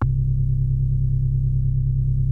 JNO 4 C2.wav